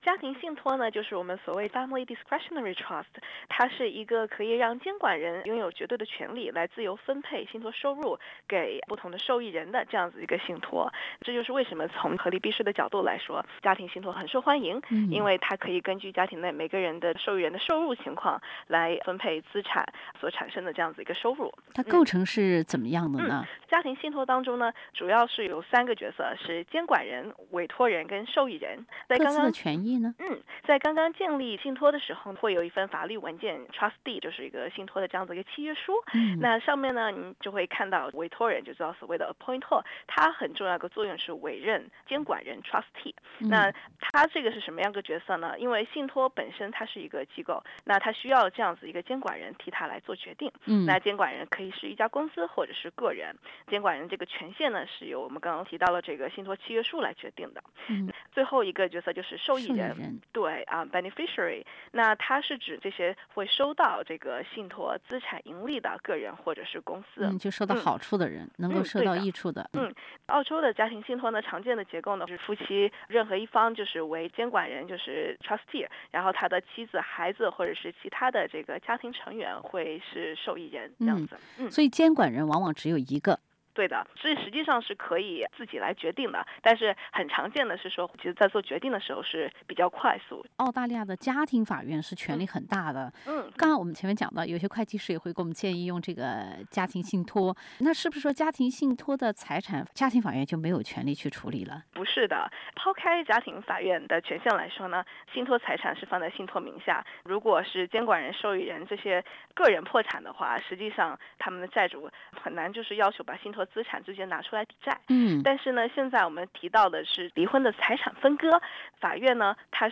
嘉宾意见，仅作参考。